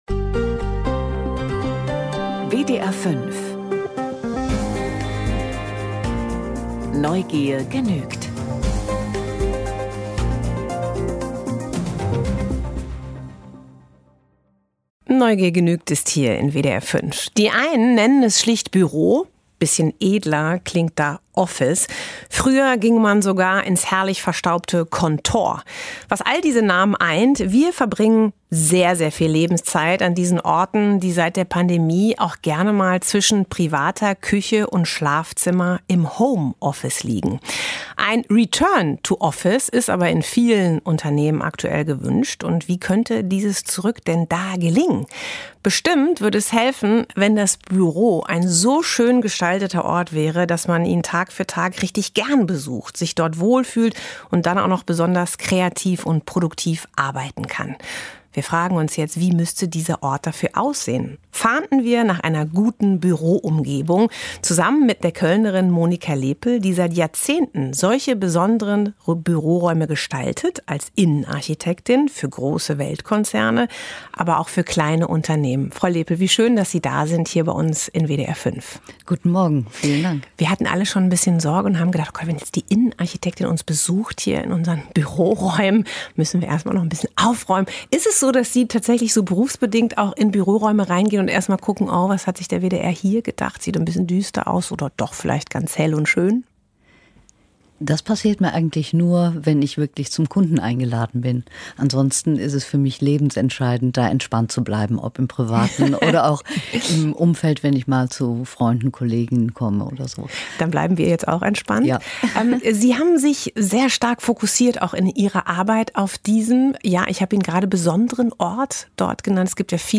Die Sendung Neugier genügt nimmt sich an jedem Werktag 25 Minuten Zeit, in der „Redezeit“ um 11.05 Uhr ein Thema zu vertiefen oder eine Person eingehend vorzustellen – in lebendigem, kritischen, manchmal heiteren Gespräch.